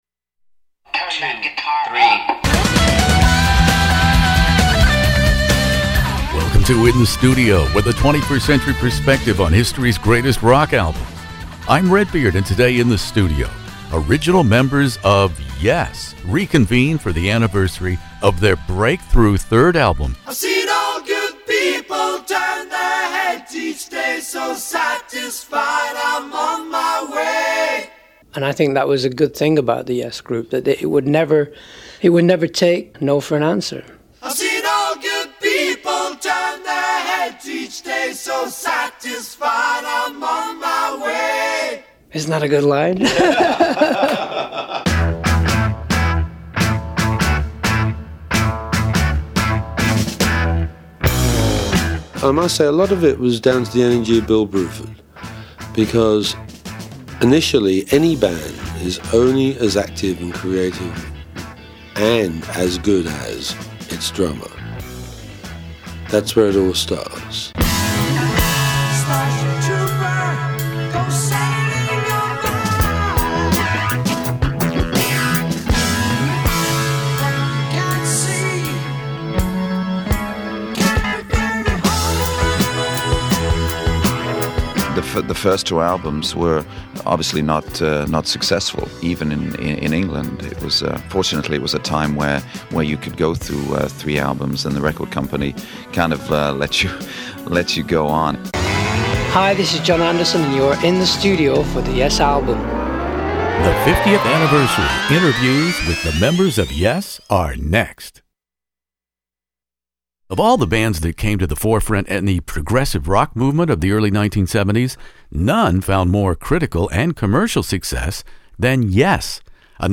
YES prodigal keyboard player Tony Kaye had returned after a long dozen year layoff, so we took advantage of that fact backstage in Dallas in discussing the group’s third album on which Kaye played way back in 1971 , The YES Album.